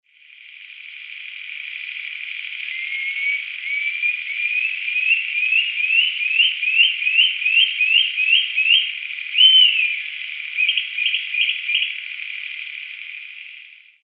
BUFF-BELLIED PUFFBIRD Notharchus swainsoni ATLANTIC FOREST
Call recorded Sapucaí, Departamento Caaguazú